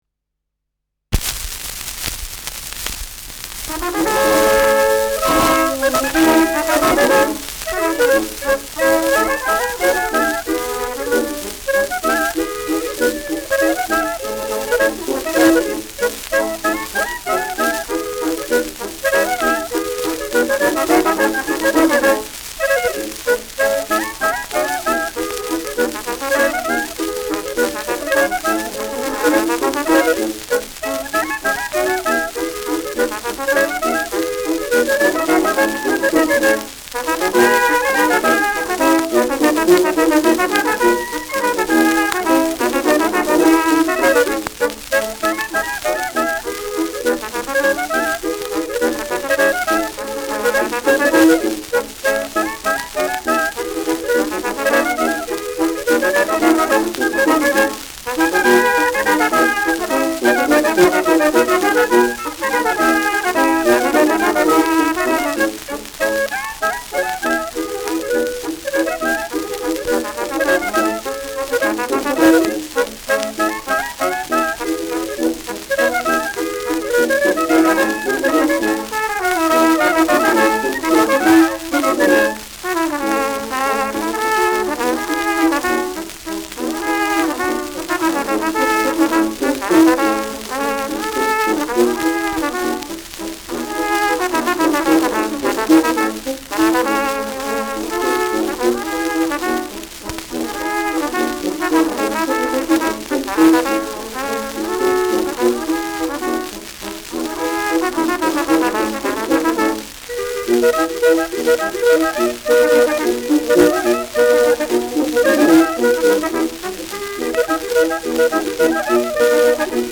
Schellackplatte
Tonrille: graue Rillen : leichte Kratzer durchgängig
präsentes Rauschen